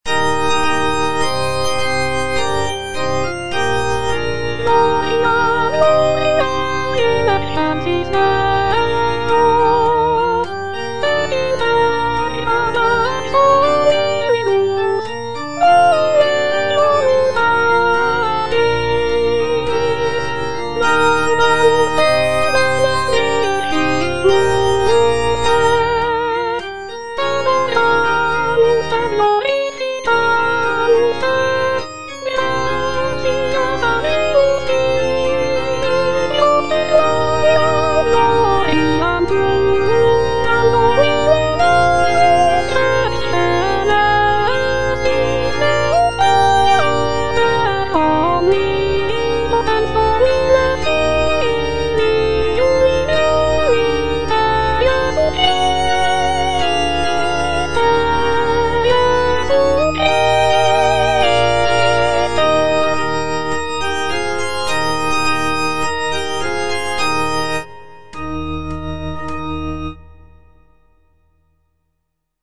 G. FAURÉ, A. MESSAGER - MESSE DES PÊCHEURS DE VILLERVILLE Gloria - Soprano (Voice with metronome) Ads stop: auto-stop